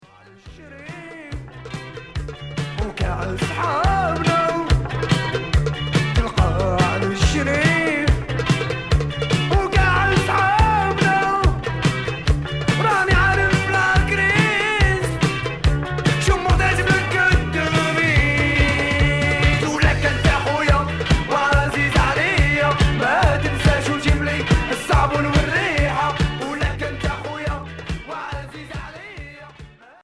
Rock raï